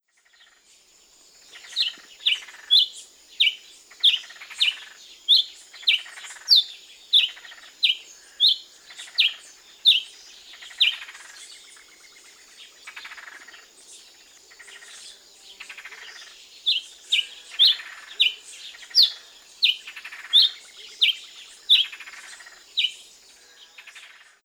Tachyphonus rufus - Frutero negro
Tachyphonus  rufus - Frutero negro.wav